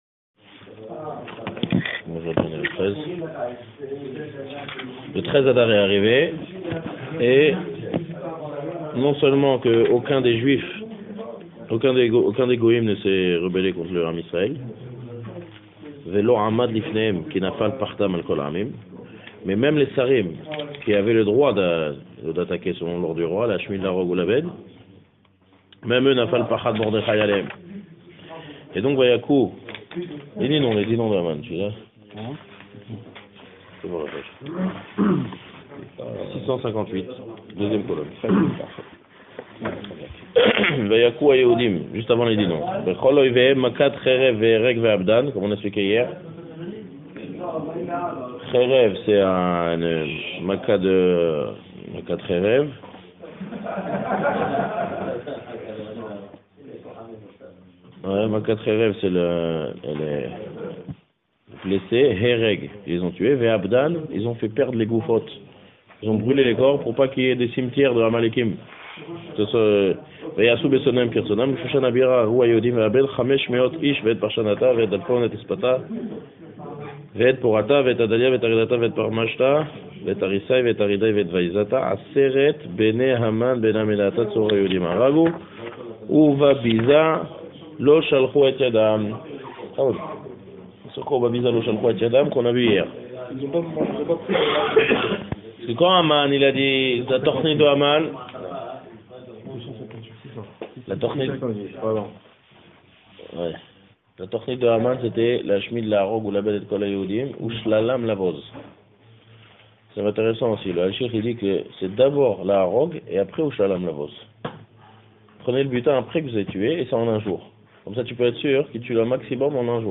Septième cours sur la Méghillat Esther; donné le 4 mars 2015 à Raanana. série de cours sur la Méghillat Esther débutée en 2014.